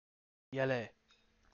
(ʲ)e
ಎಲೆ (y)ele 'leaf' yes